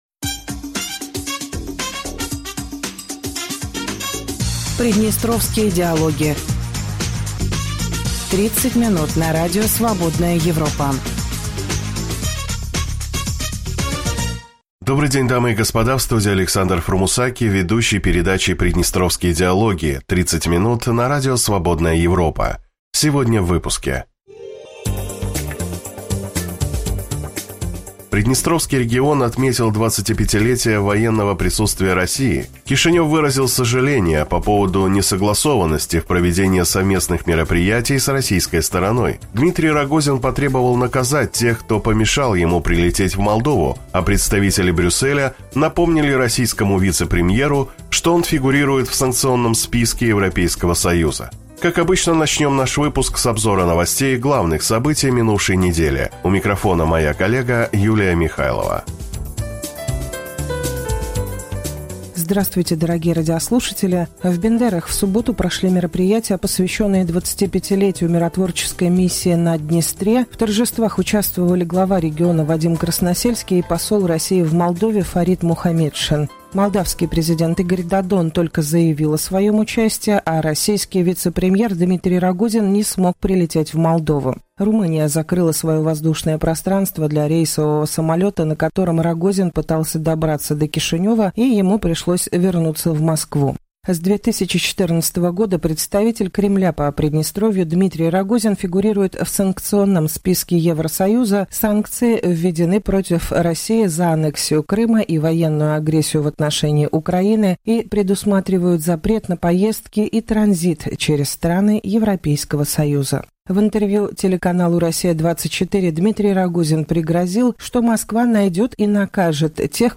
Дорогие радиослушатели, добрый день.